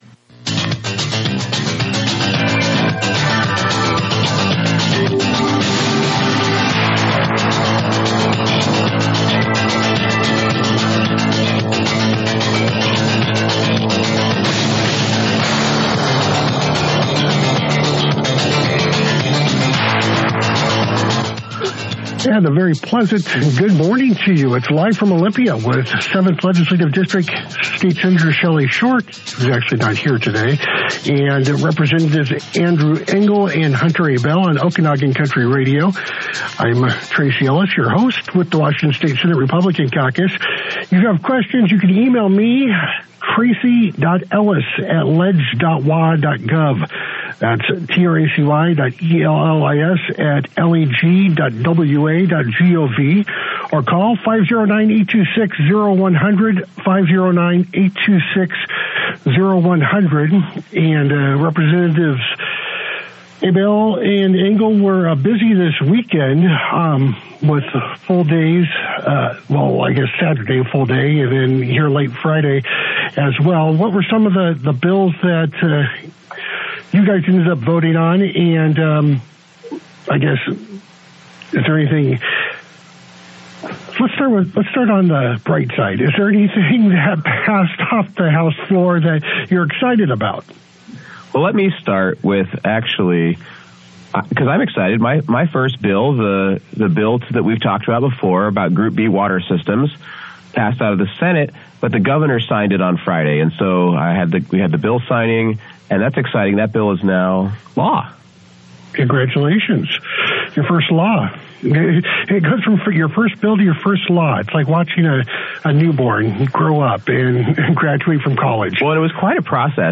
On Okanogan Country Radio, 7th District Representatives Andrew Engell and Hunter Abell discuss recent legislative highlights, including the passage of Engell’s Group B water systems bill and debates on mandatory reporting for clergy, unemployment benefits for striking workers, and property tax increases.